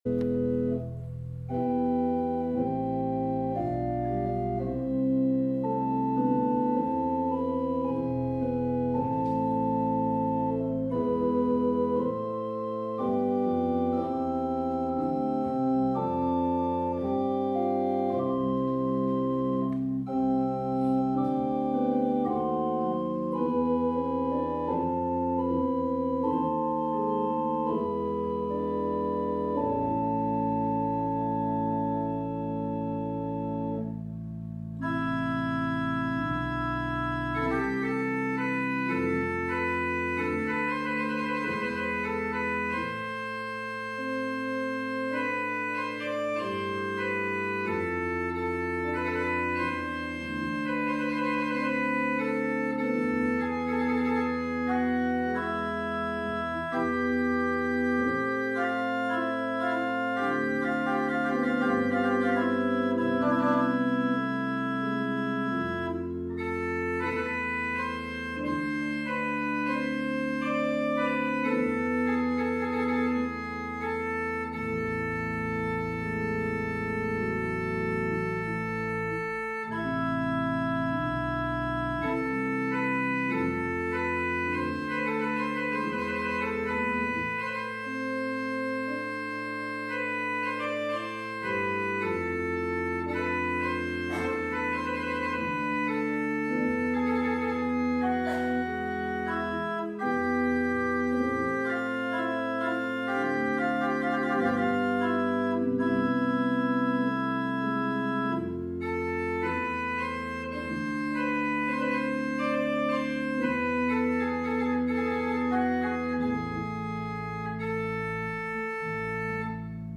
The recording begins with the special music.